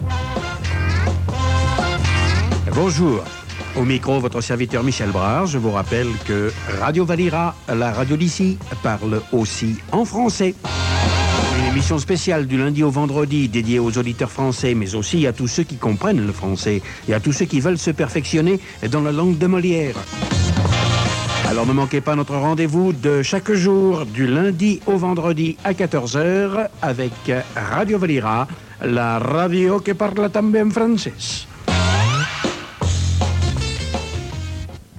ec59d17caa456363e08c6d2a6c4cf7a2367dfe4e.mp3 Títol Ràdio Valira Emissora Ràdio Valira Titularitat Privada local Descripció Identificació i salutació inicial del programa parlat en francès.